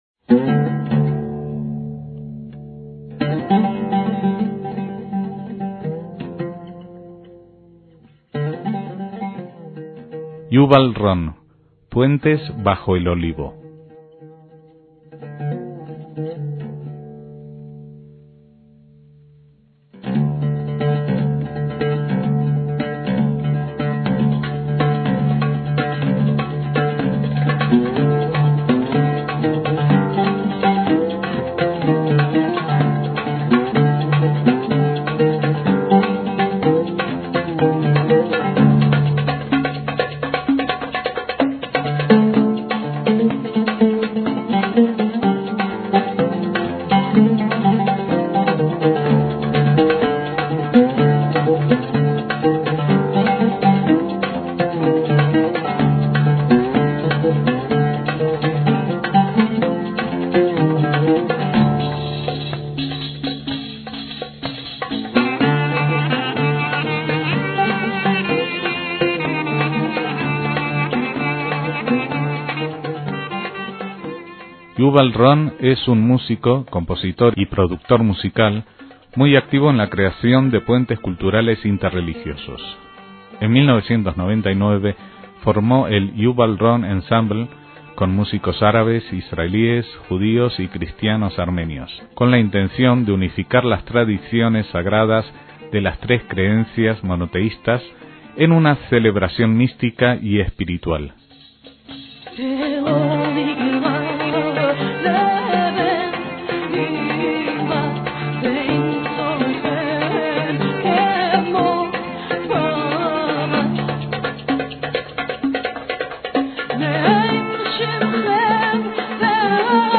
oud, saz
qanún
percusiones
teclados